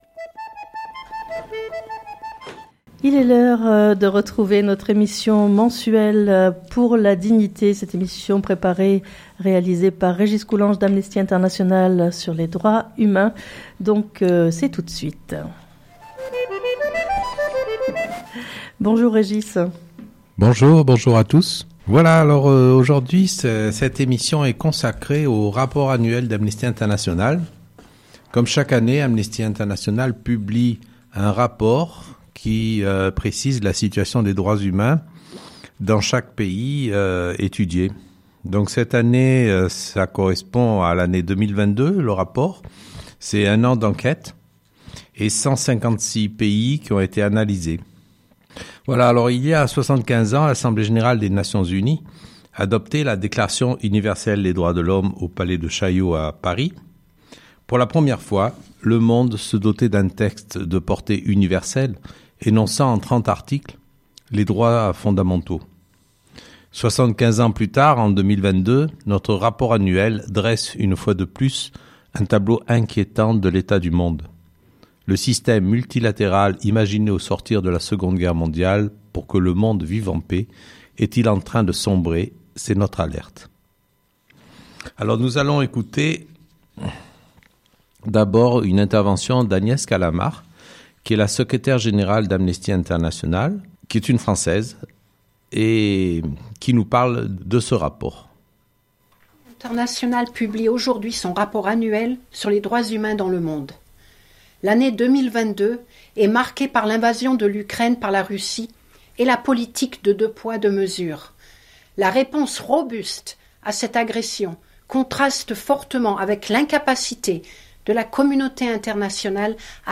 Emission spéciale sur le rapport annuel